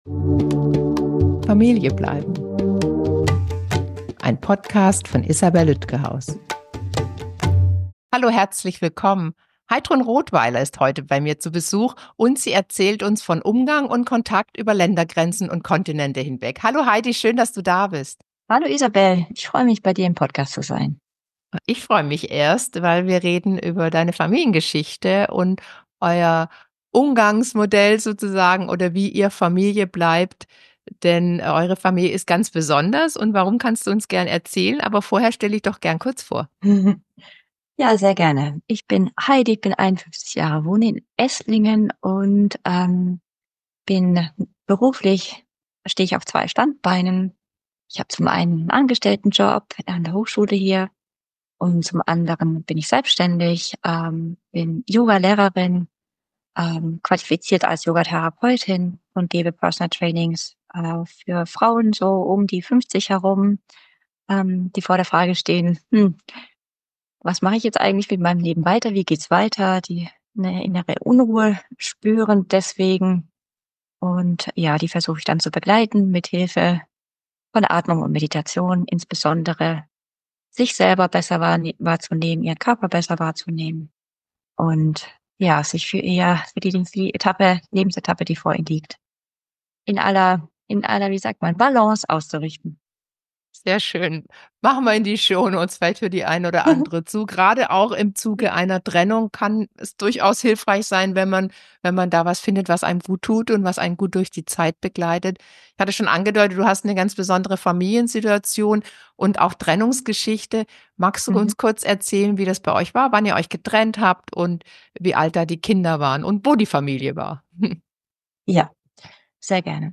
Familieninterview Teil 1